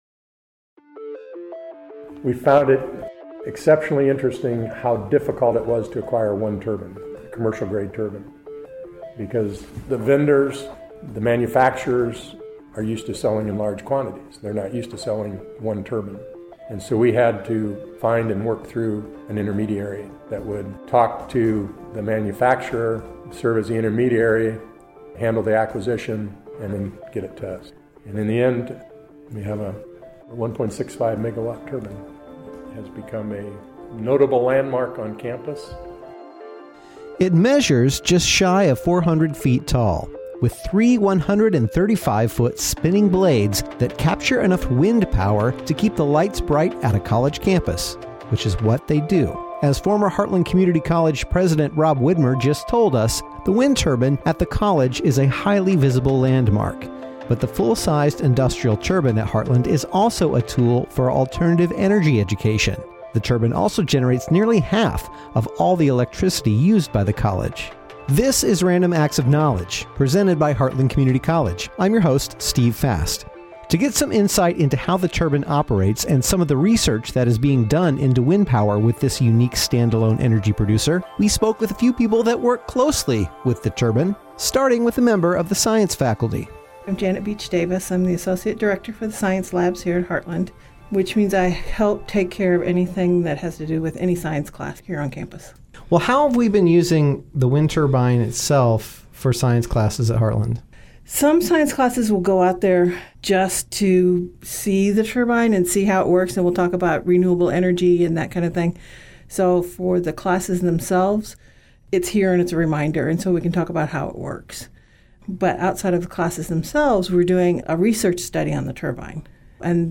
Administration, faculty and staff involved with the Heartland wind turbine weigh in.